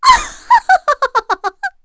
Erze-Vox_Happy3_kr.wav